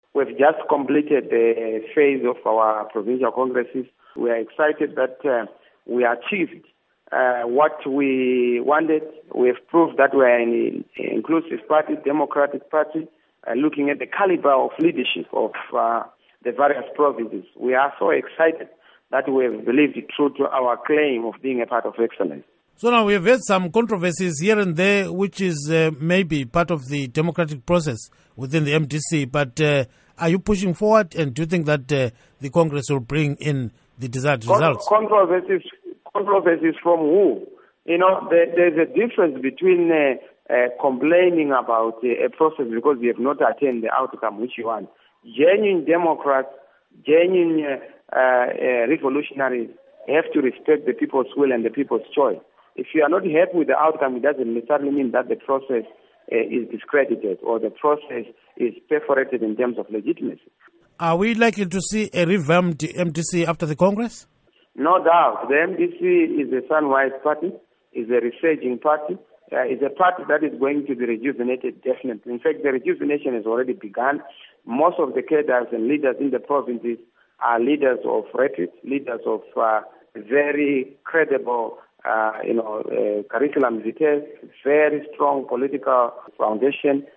Interview With MDC-T Organizing Secretary Nelson Chamisa